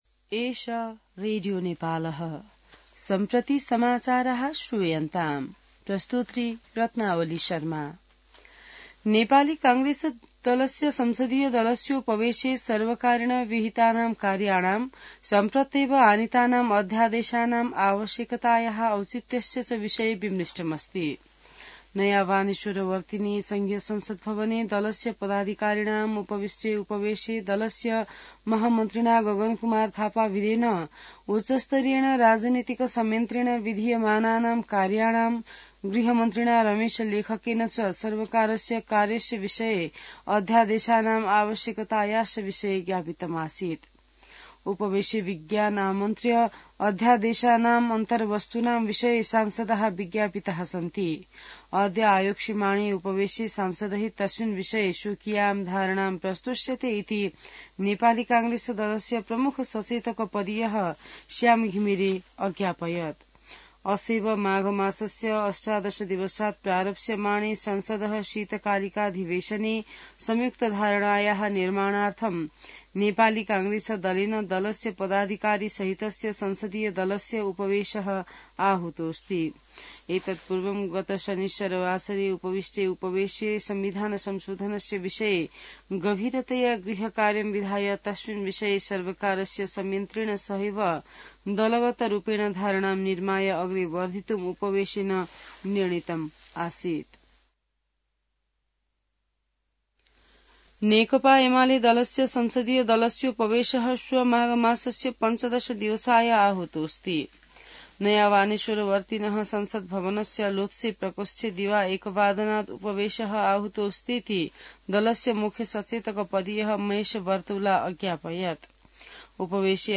संस्कृत समाचार : १५ माघ , २०८१